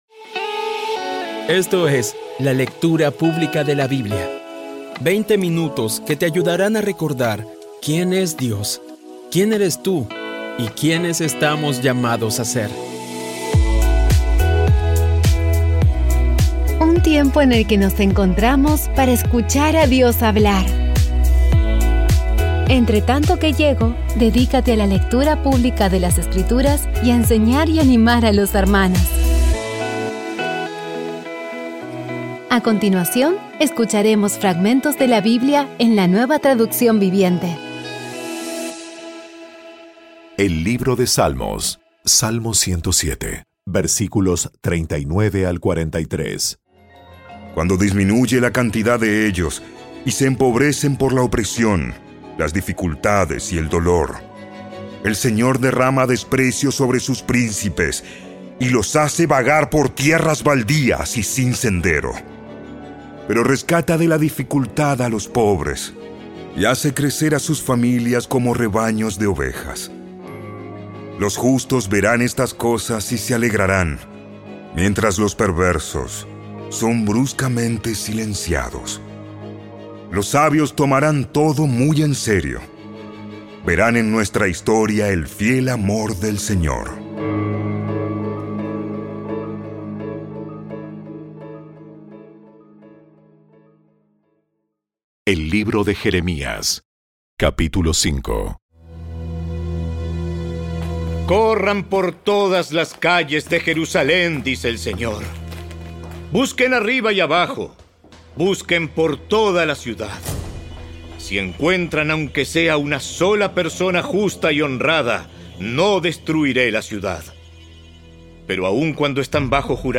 Audio Biblia Dramatizada Episodio 273
Poco a poco y con las maravillosas voces actuadas de los protagonistas vas degustando las palabras de esa guía que Dios nos dio.